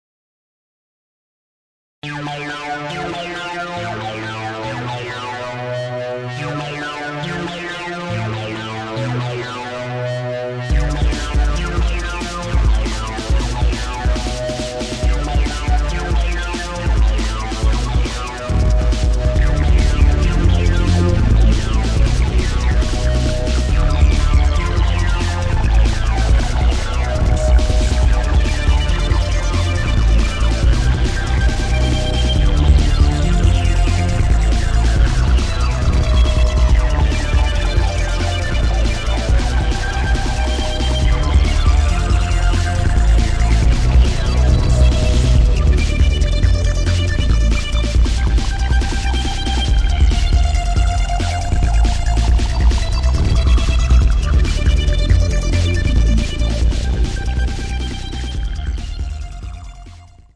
sound example: industrial 2 (like the previous track but with bass pad from the sw60xg.) and here is a sound example of the sw60 by itself: